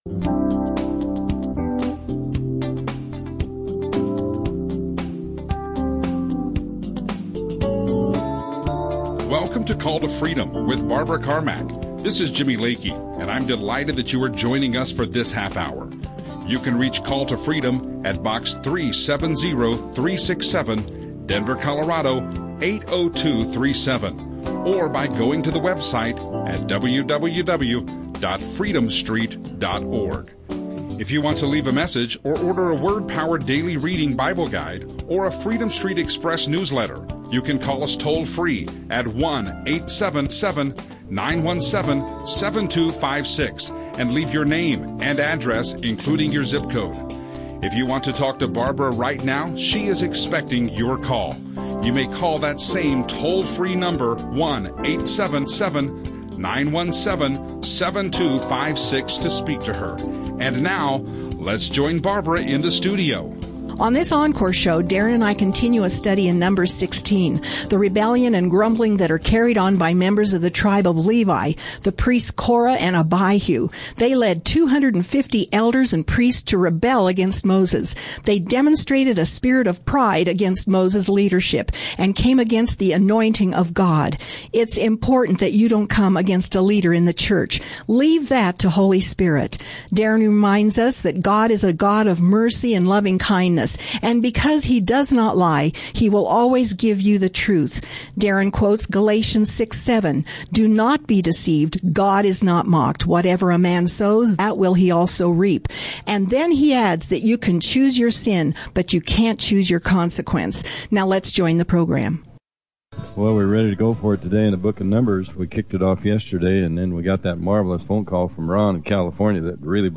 Radio shows